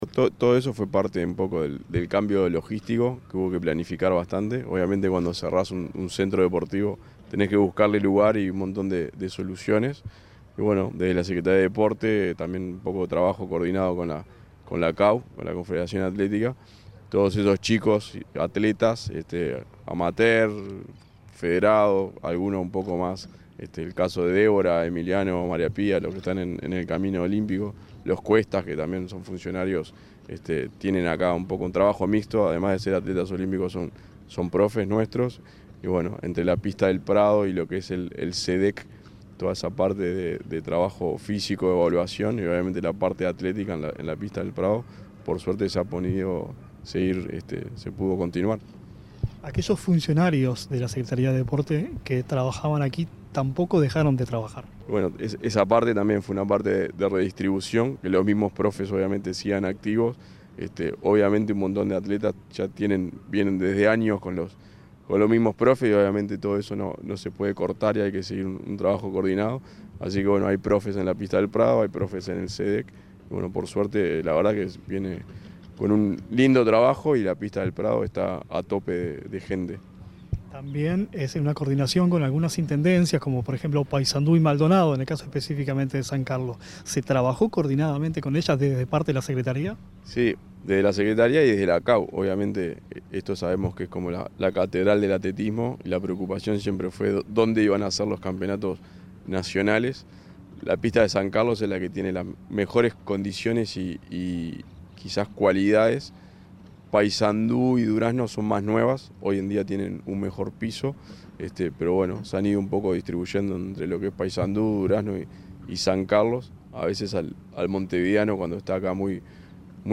Entrevista al director de Deportes Federados de la SND, Eduardo Ulloa